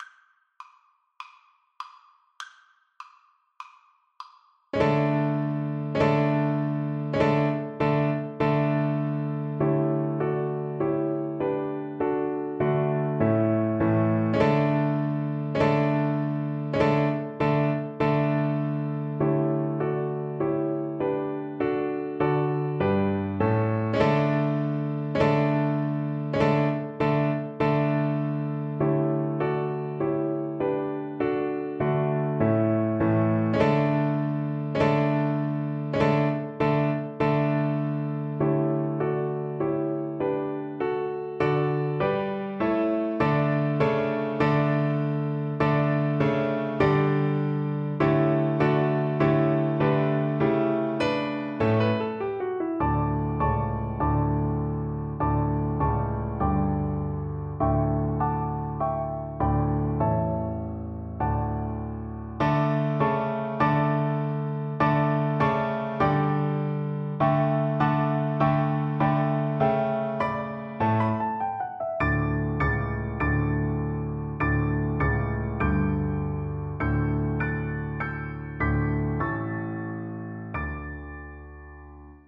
Play (or use space bar on your keyboard) Pause Music Playalong - Piano Accompaniment Playalong Band Accompaniment not yet available transpose reset tempo print settings full screen
Violin
March = c.100
4/4 (View more 4/4 Music)
D major (Sounding Pitch) (View more D major Music for Violin )
Traditional (View more Traditional Violin Music)
Scottish